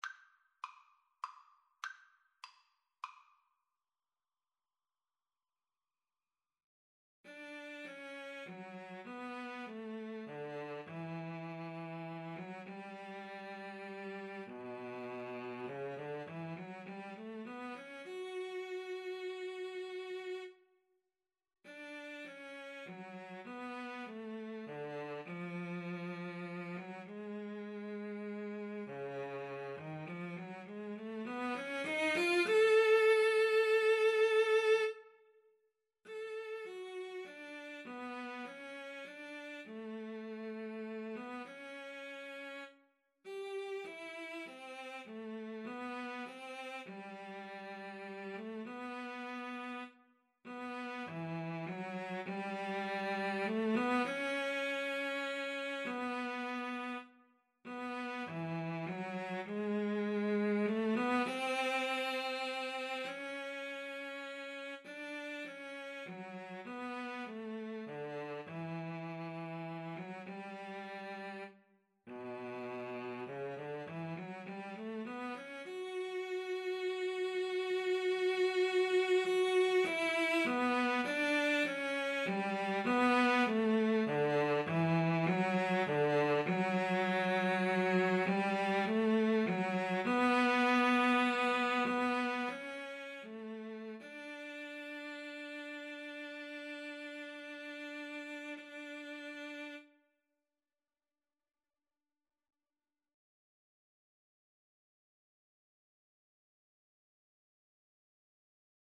3/4 (View more 3/4 Music)
Cantabile
Classical (View more Classical Guitar-Cello Duet Music)